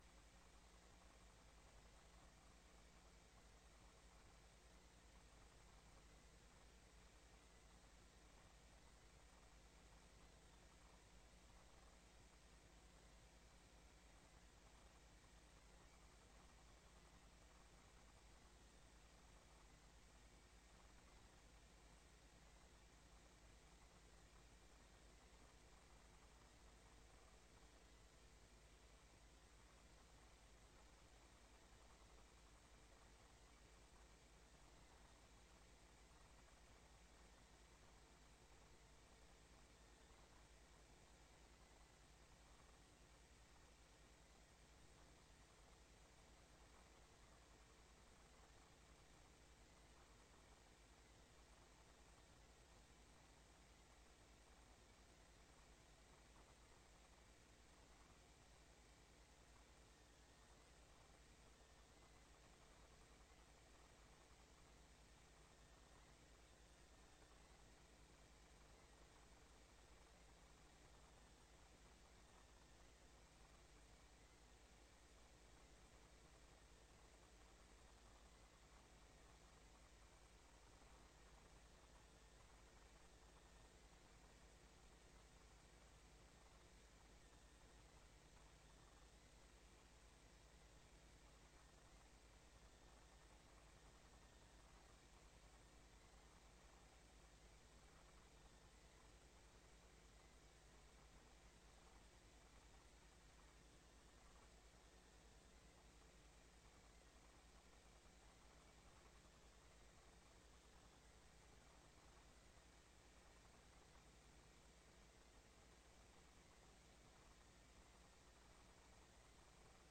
Download de volledige audio van deze vergadering
Het college gaat, samen met Hecht, met de raad in gesprek over de bezuinigingen bij Hecht. Hecht geeft een korte presentatie over het gelopen proces, de impact en het vervolg van de bezuinigingen.